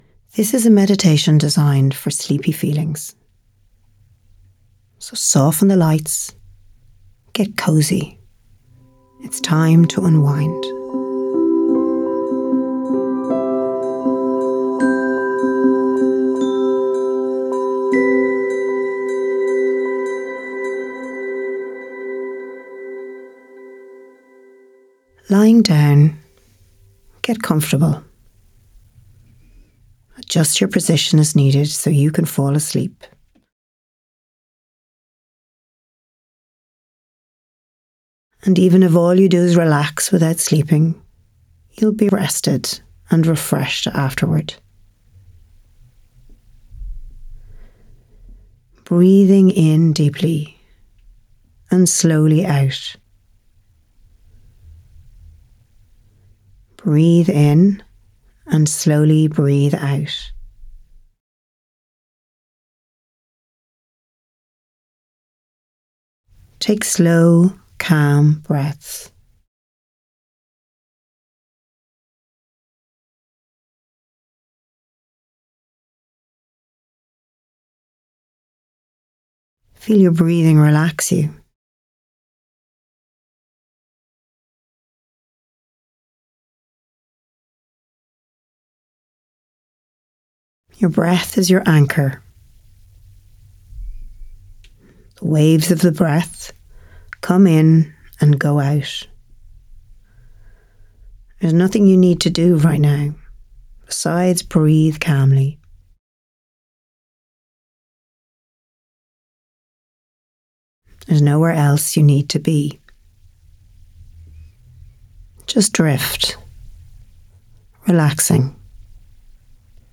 Unwind-Meditation-Sleepy-Feelings.mp3